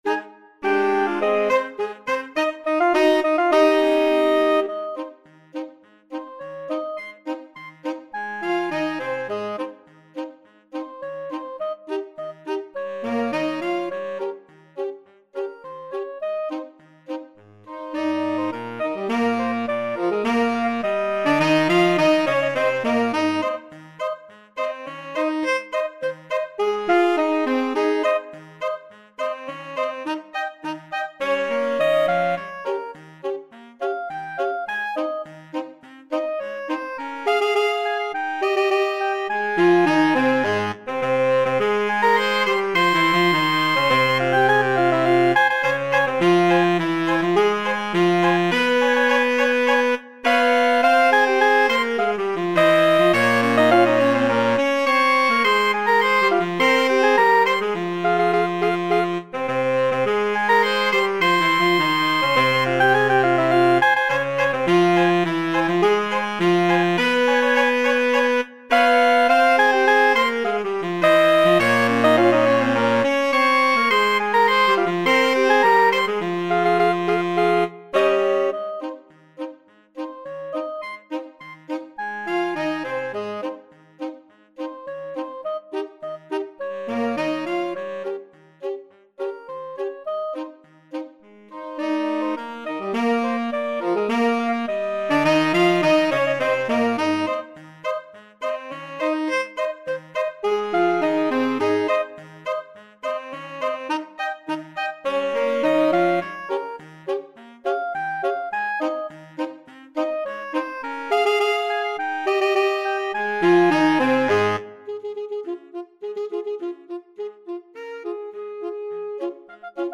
Quick March = c.104
2/2 (View more 2/2 Music)
Classical (View more Classical Saxophone Quartet Music)